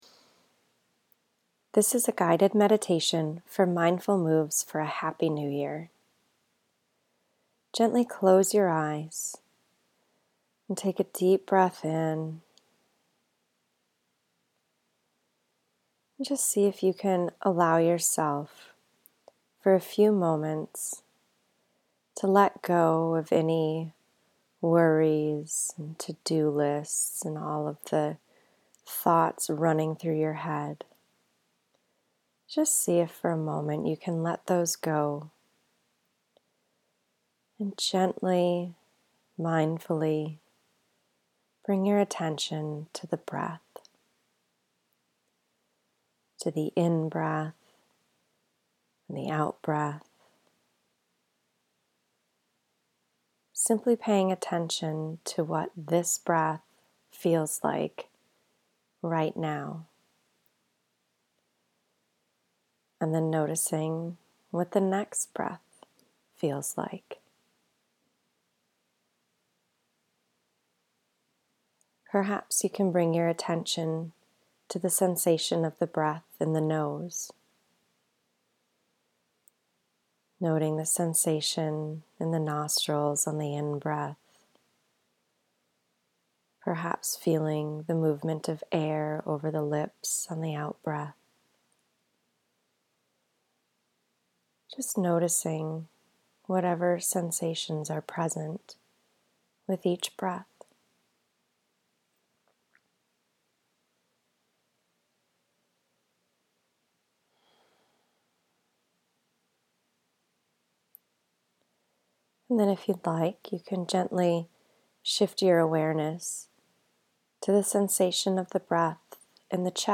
To help you get started, I’ve developed this three-minute mindfulness meditation audio guide for you.
Breath Meditation}
HNY-Breath-Meditation.mp3